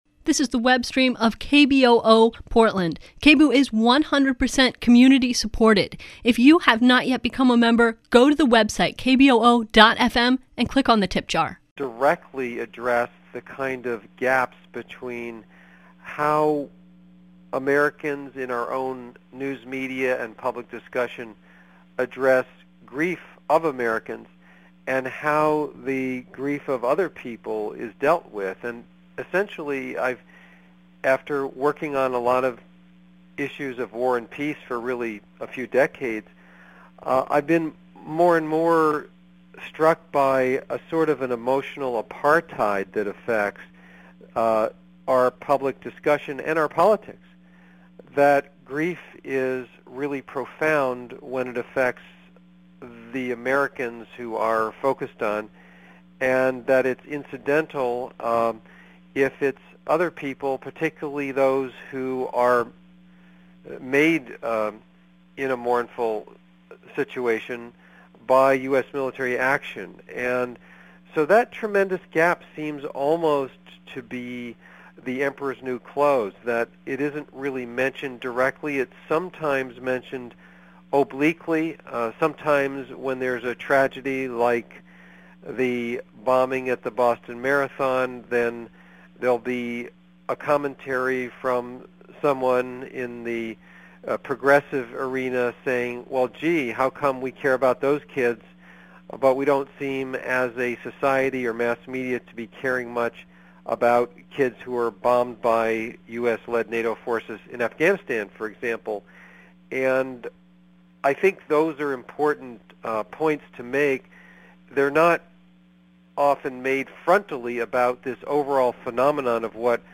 With call-ins.